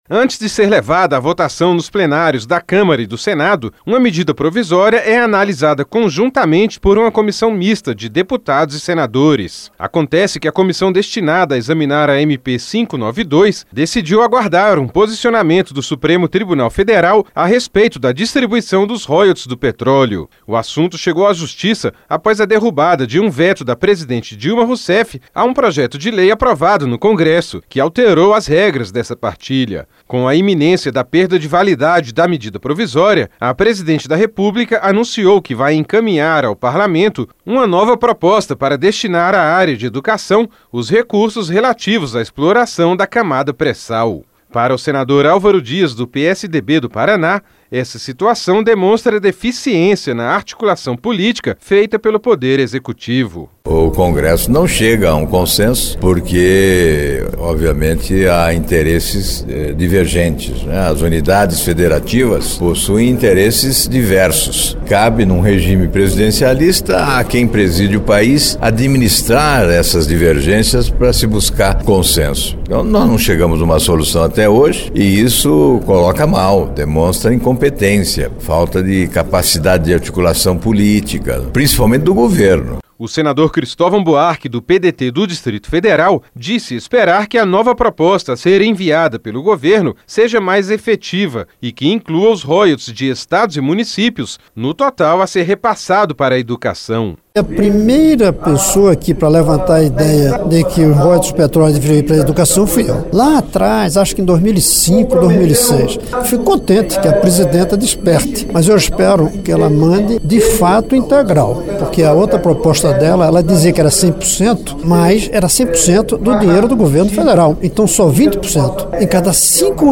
(Repórter) Antes de ser levada à votação nos plenários da Câmara e do Senado, uma medida provisória é analisada conjuntamente por uma comissão de deputados e senadores.